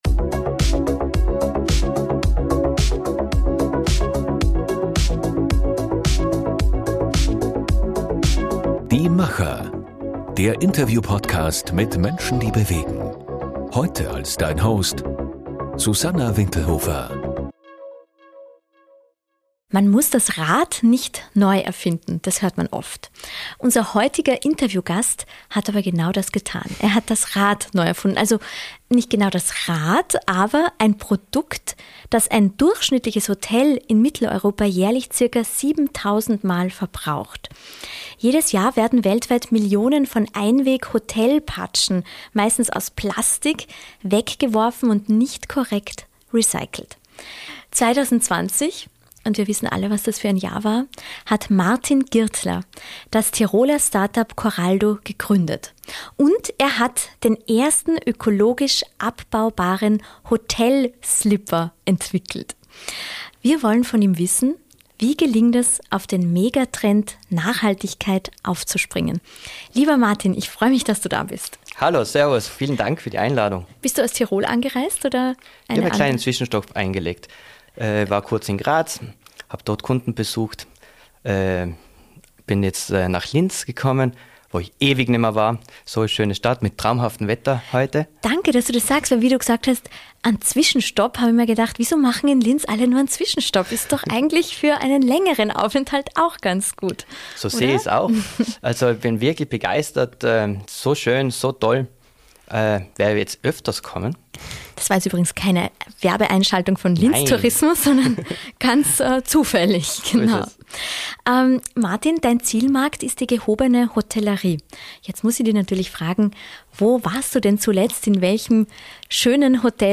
Unser heutiger Interviewgast hat aber genau das getan.